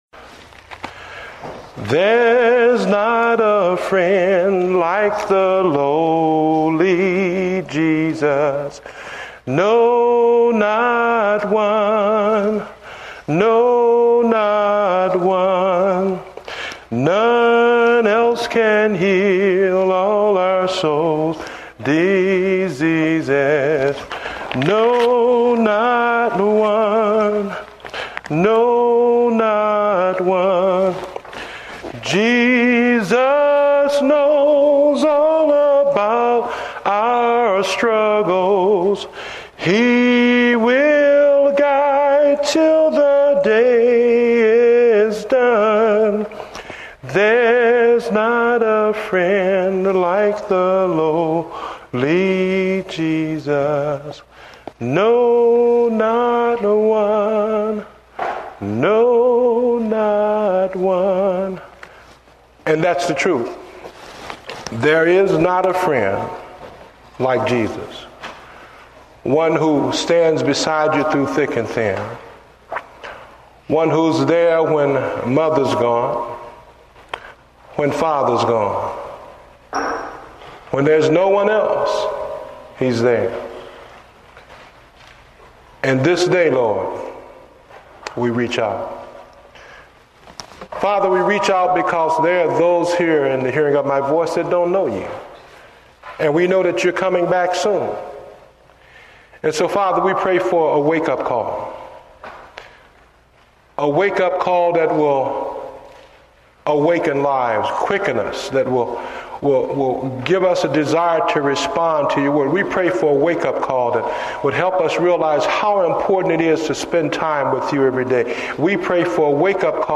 Date: July 13, 2008 (Morning Service)